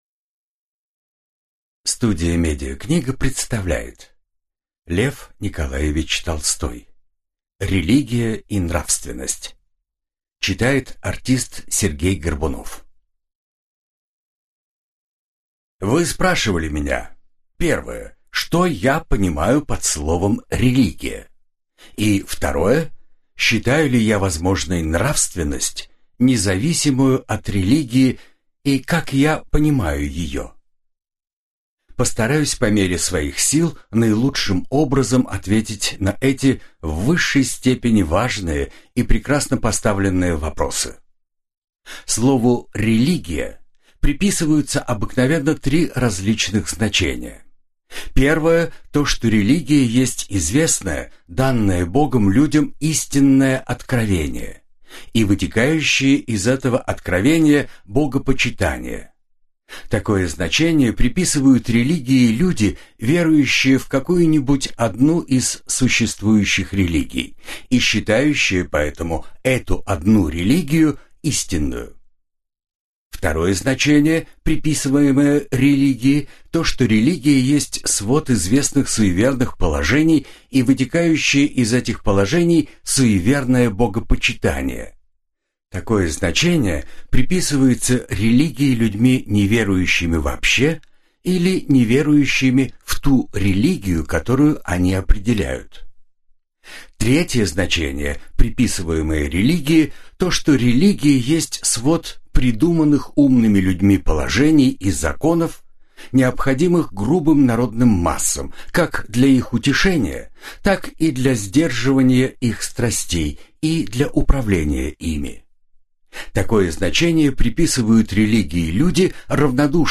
Аудиокнига Религия и нравственность | Библиотека аудиокниг